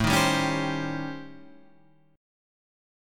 A7#9b5 chord